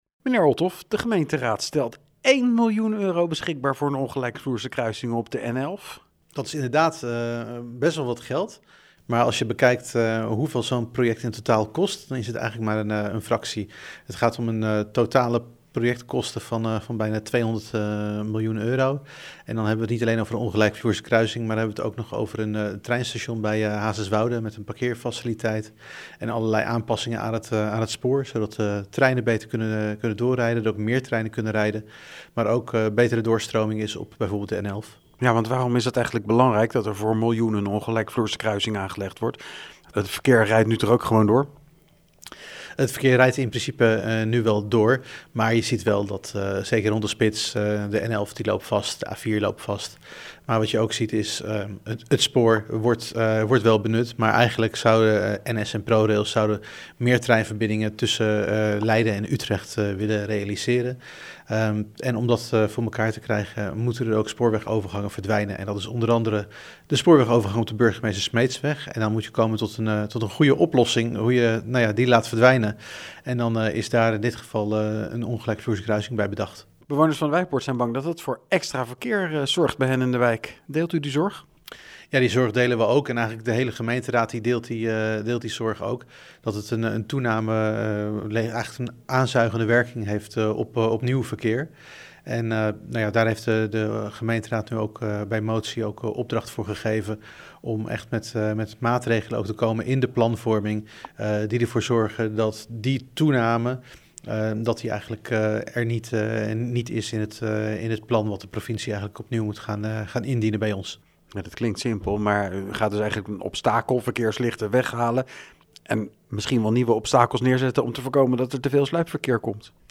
Wethouder Paul Olthof over de miljoen euro voor een ongelijkvloerse kruising: